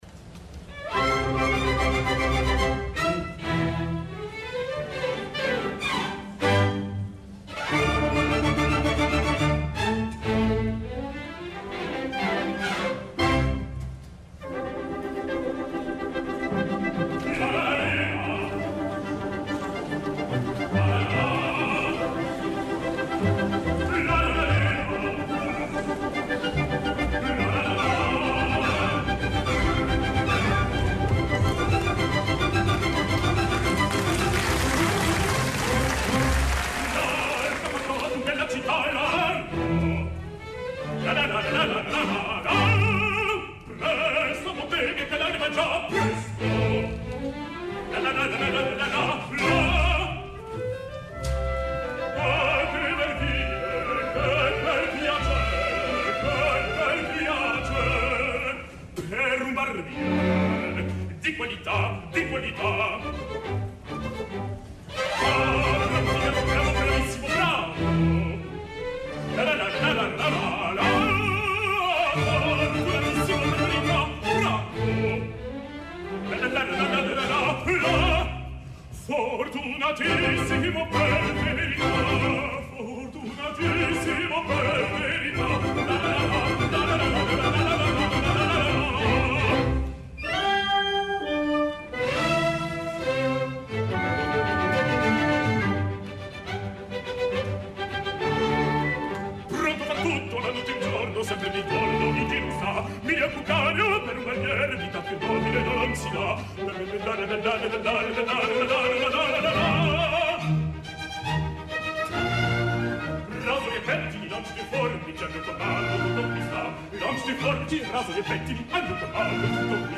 Tot i així la segona gravació que he aconseguit de la transmissió, està “millorada” respecte al que vaig escoltar.
Sort que una d’aquestes veus aprofitables va ser la del barber protagonista, a càrrec d’un jove baríton francès (baríton de veritat) que ja em va sorprendre molt positivament en el concert del 30 è aniversari de Les Musiciens du Louvre que va ser motiu d’un apunt, el nom Florian Sempey.